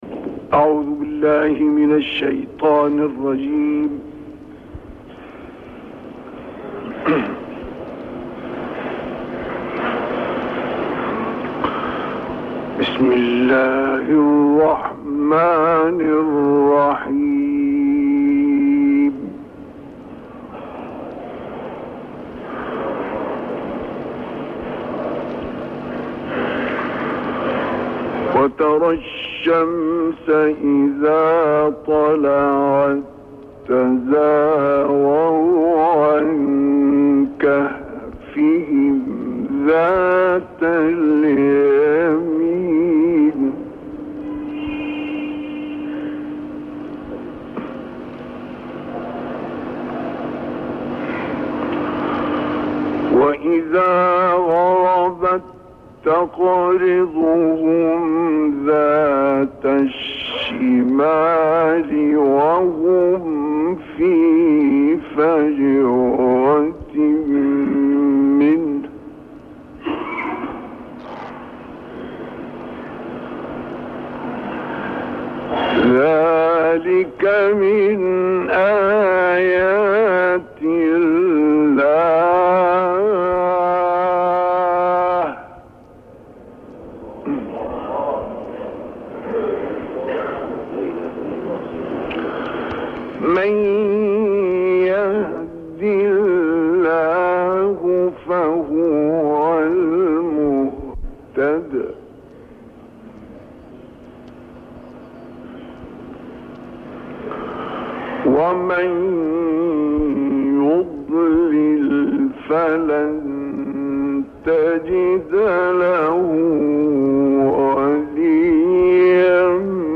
تهران - الکوثر : شیخ مصطفی اسماعیل قاری برجسته مصری بیست و دوم دسامبر 1978 ، چهار روز پیش از وفات ، این تلاوت زیبا را که آیات 17 تا 34 سوره کهف را شامل می شود در مسجد جامع البحر در دمیاط مصر اجرا کرد.